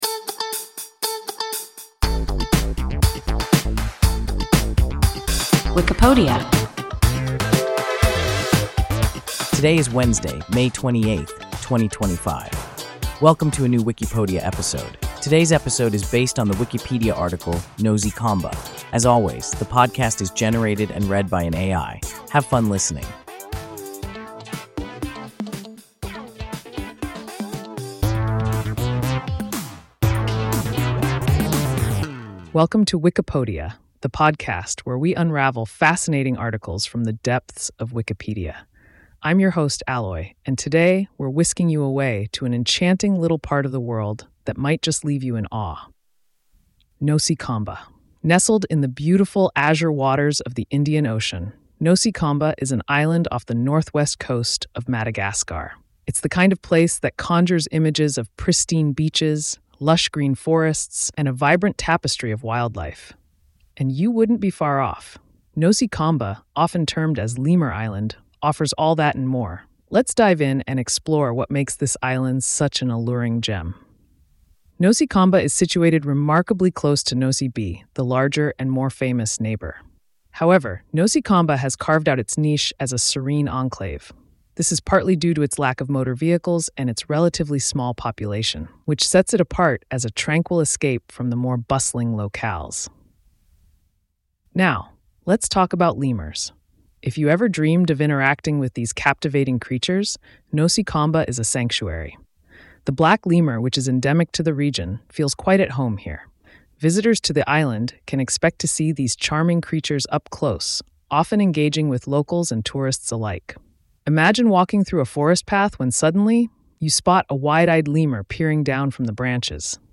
Nosy Komba – WIKIPODIA – ein KI Podcast